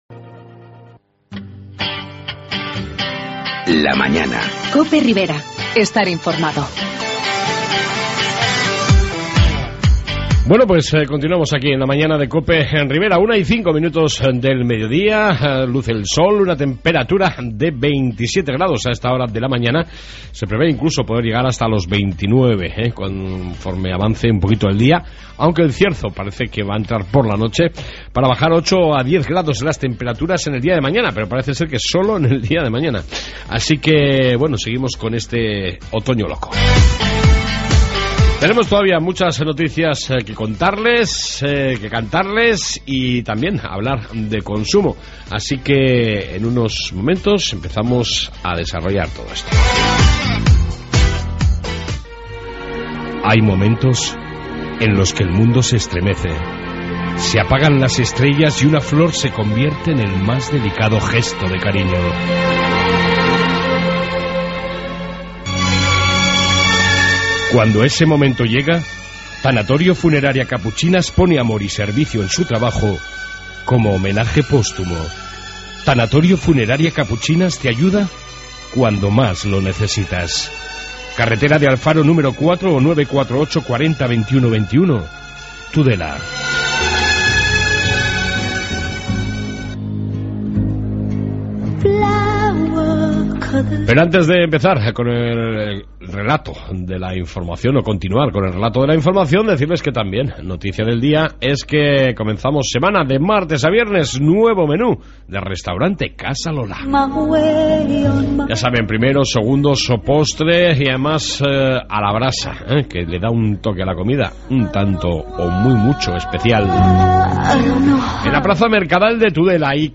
AUDIO: En esta 2 parte seguimos con la Información y entrevista sobre Consumo...